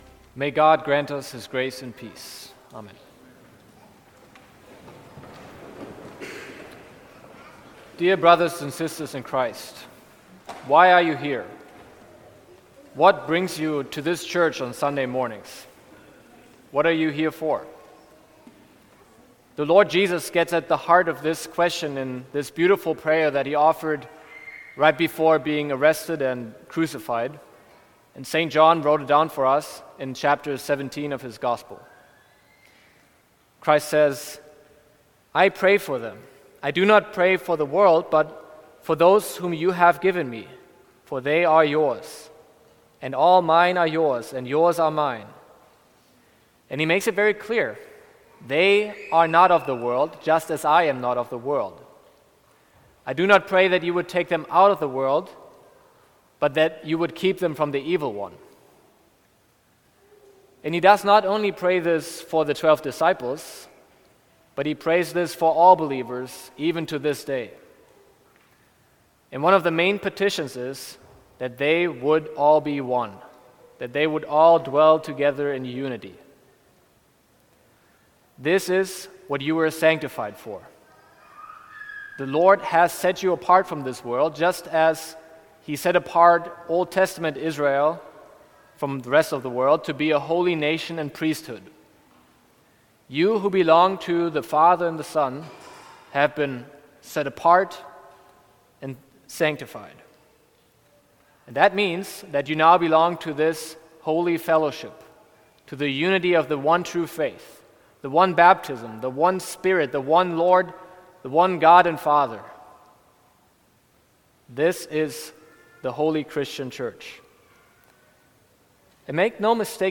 Sermon for Seventh Sunday of Easter